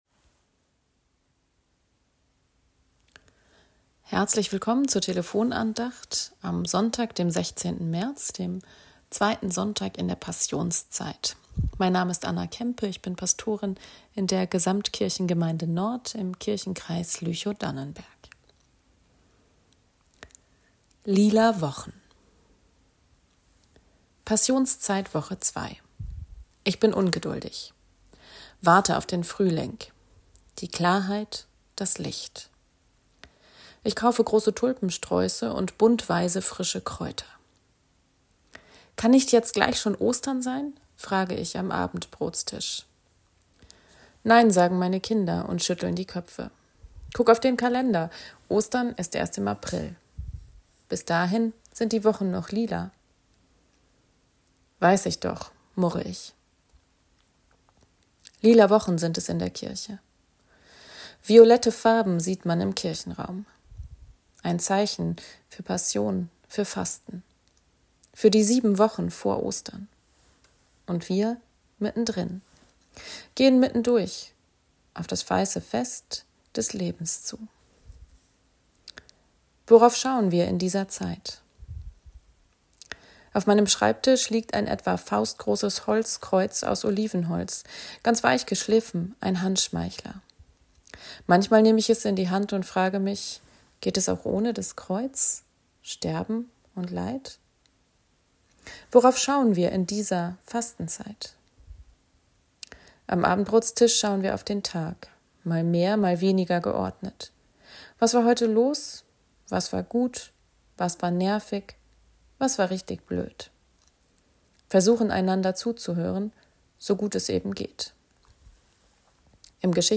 Lila Wochen ~ Telefon-Andachten des ev.-luth. Kirchenkreises Lüchow-Dannenberg Podcast